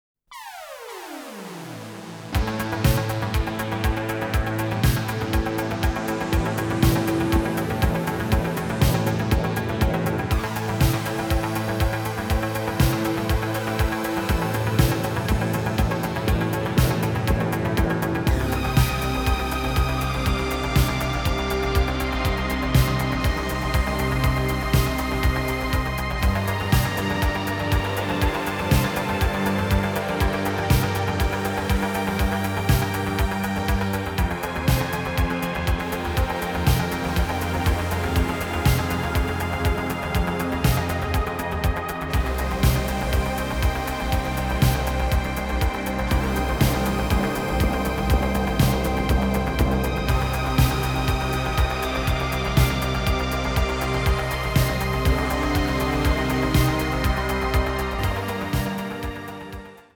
the usual eighties sound of synthesizers
a sort of new-age jazz mood
both are in pristine stereo sound.